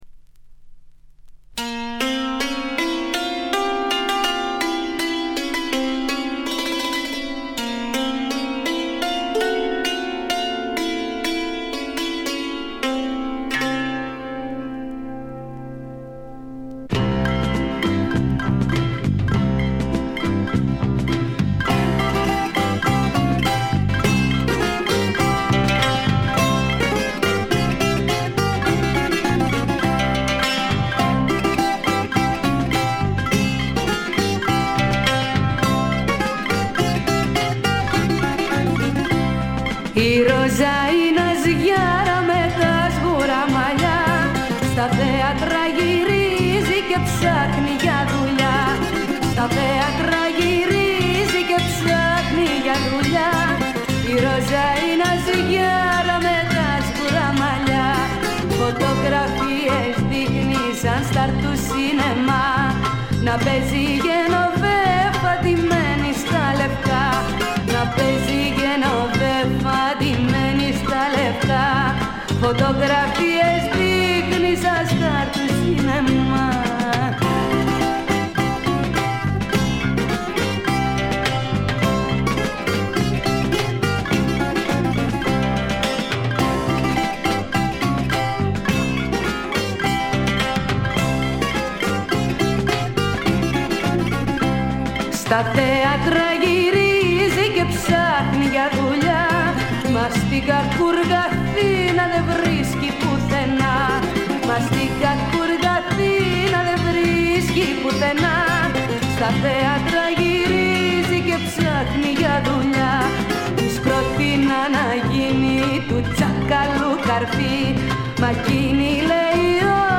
わずかなノイズ感のみ。
しかしデビュー時にしてすでに堂々たる歌唱を聴かせてくれます。
試聴曲は現品からの取り込み音源です。